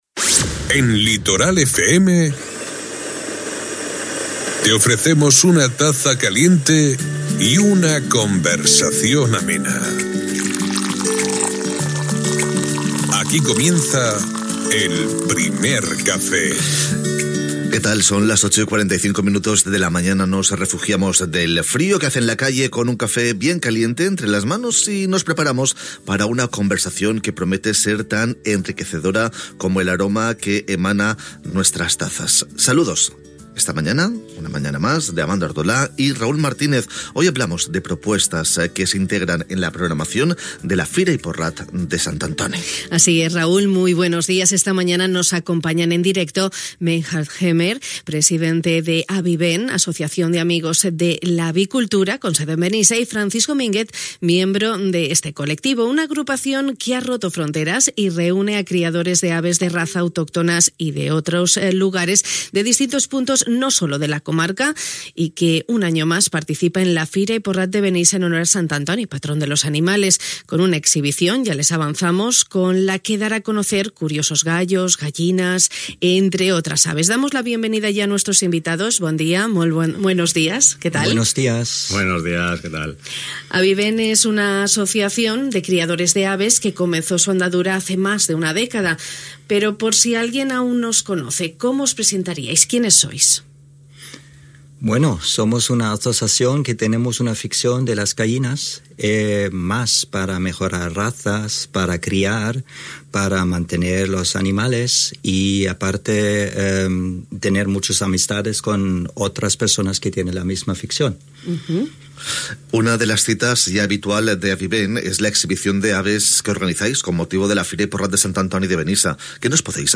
Aquest matí ens han acompanyat en directe